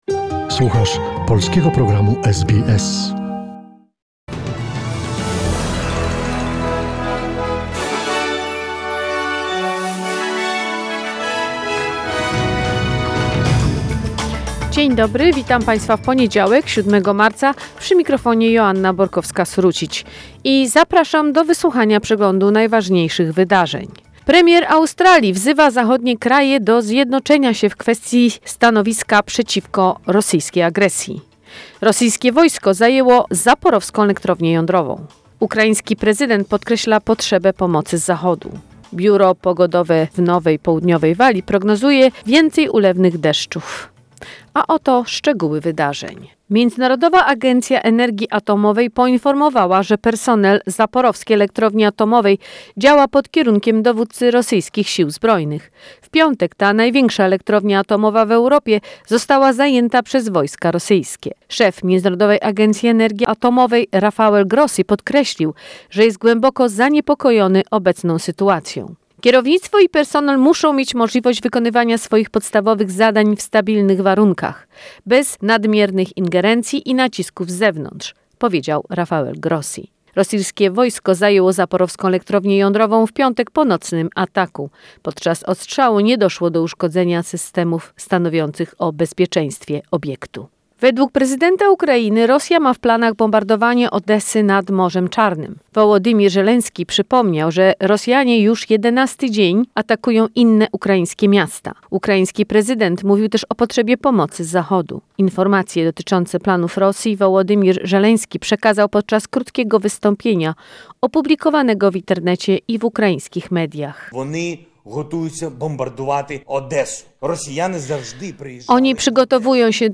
SBS News in Polish, 7 March 2021